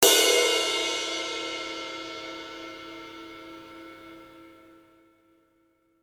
音の立ち上がりが良く、歯切れの良いサウンドのメタル・クラッシュ18”